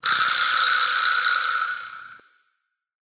「カーッ！」という乾いた甲高い揺れる音。